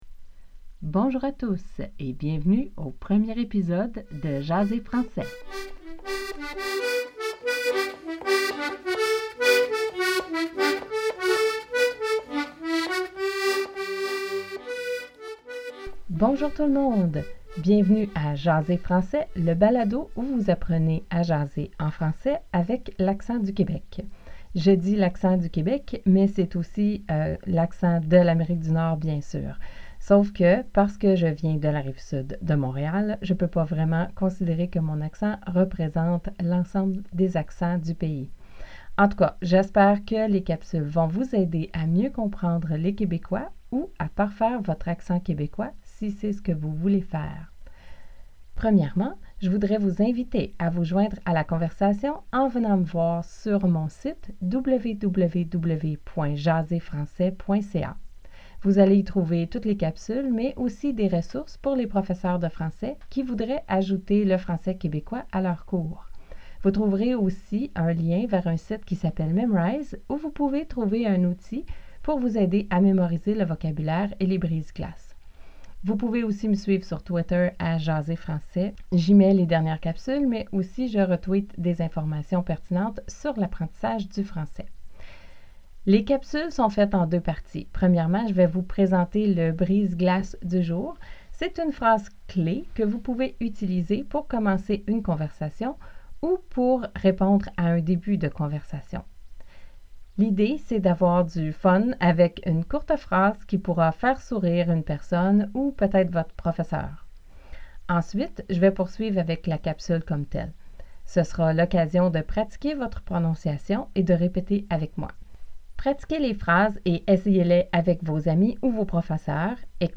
J’ai = J’ai / J’ai pas   – j’ai un chat – j’ai pas d’chat
Tu as = T’as / T’as pas